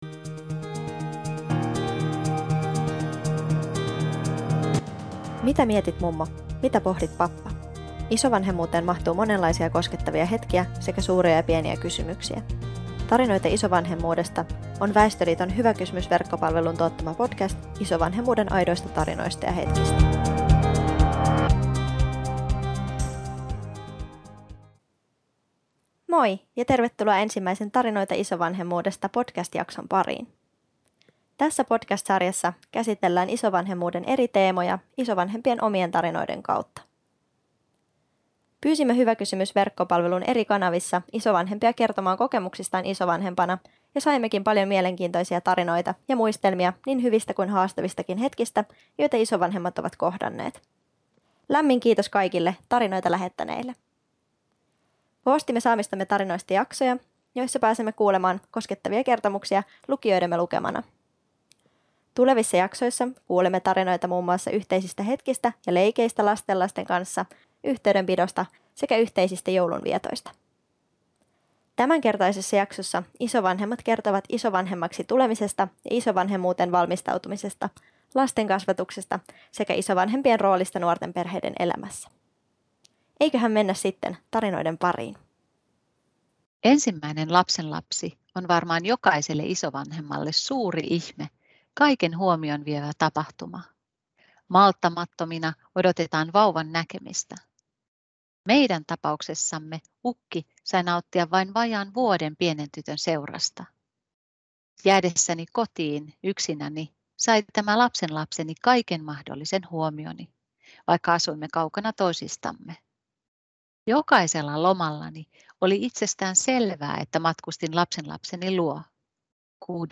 Podcastissa käsitellään isovanhemmuuden eri teemoja isovanhempien omien tarinoiden kautta. Jaksoissa lukijamme lukevat nauhalle isovanhempien lähettämiä tarinoita.